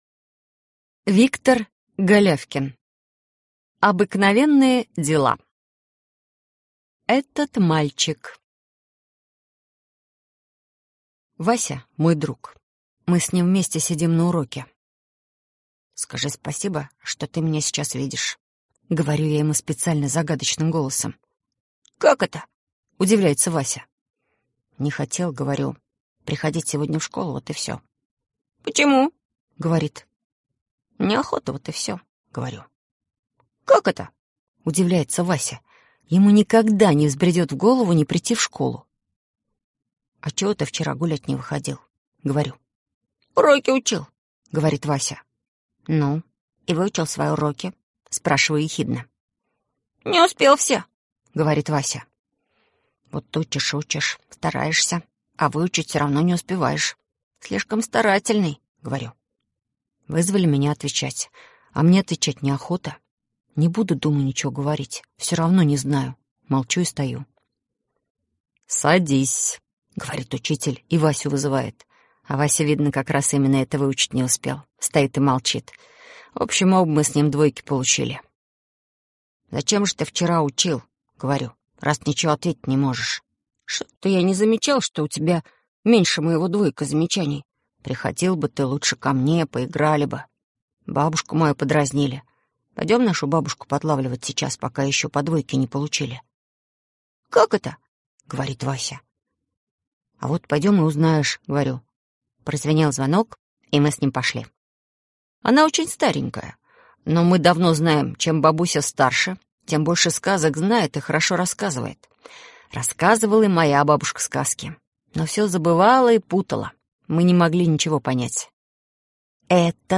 Этот мальчик - аудио рассказ Виктор Голявкина - слушать онлайн